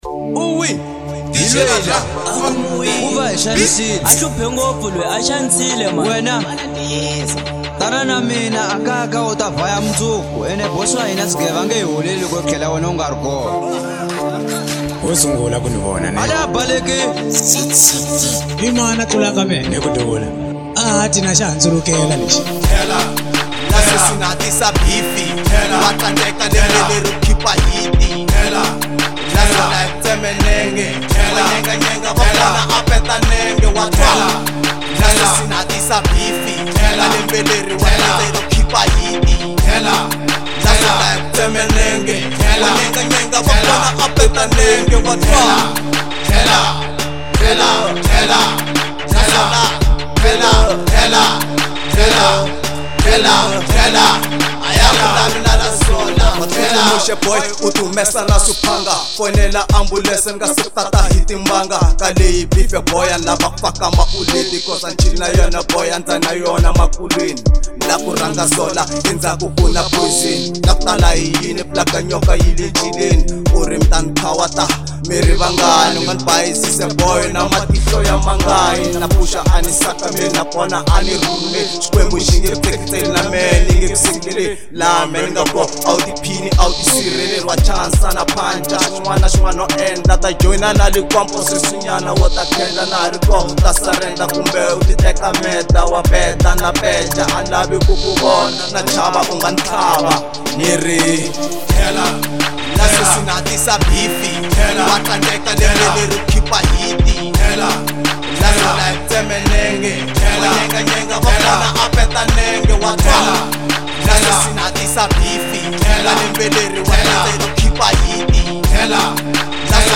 04:01 Genre : Hip Hop Size